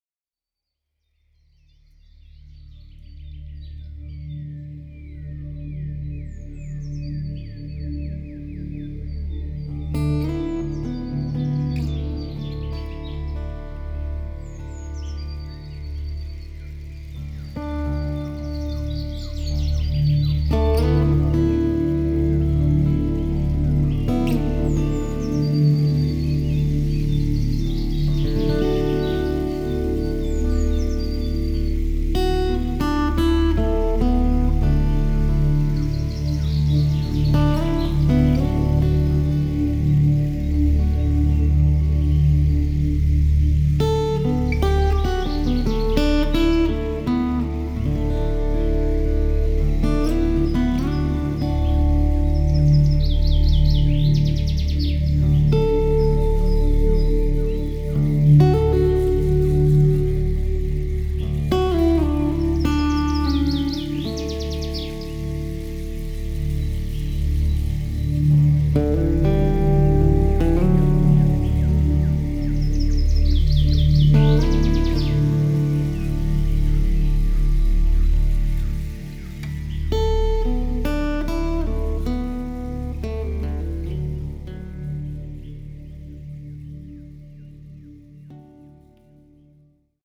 avec son audio spatial 3D